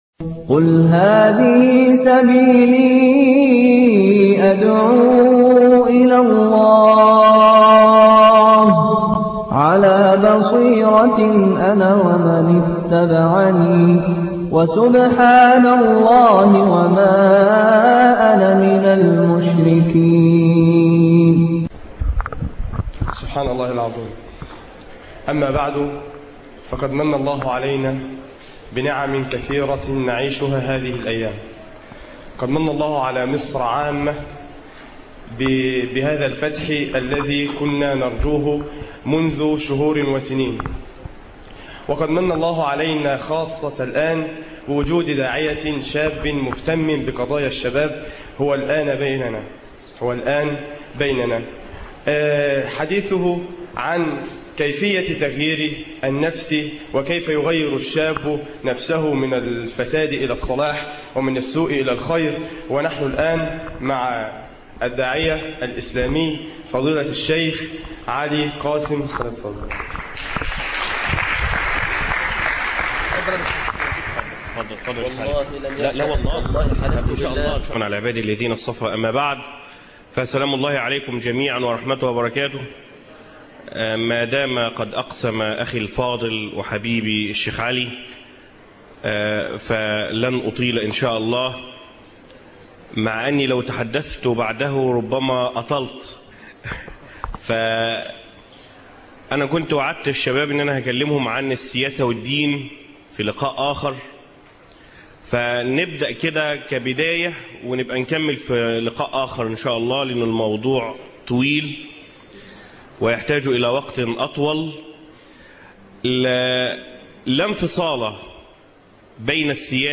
انصر دينك ..وغير نفسك (لقاء كلية دار العلوم جامعة الفيوم